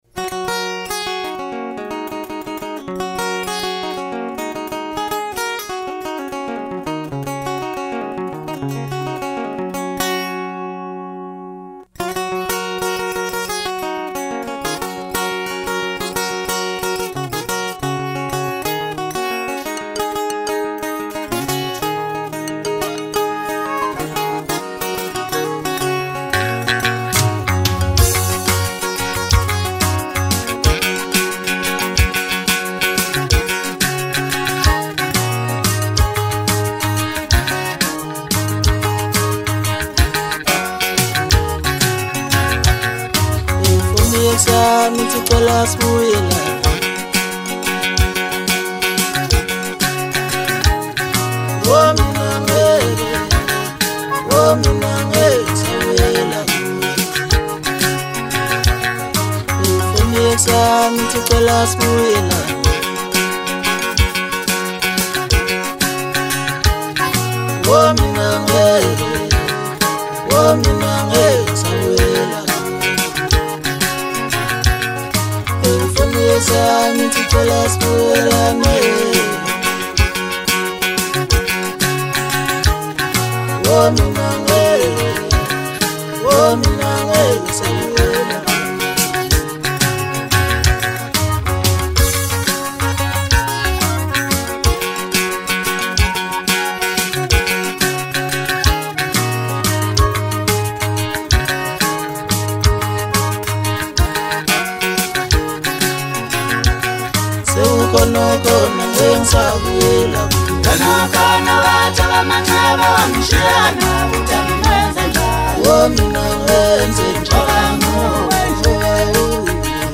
Home » Maskandi » Hip Hop